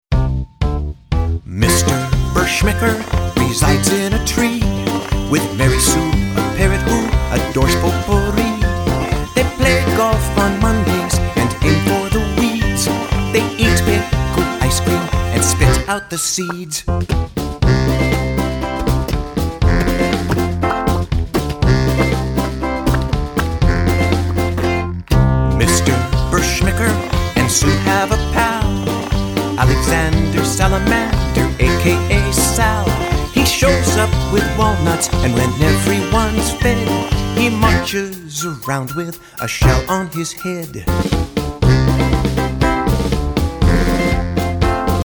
clever, cheeky, laugh-out-loud funny songs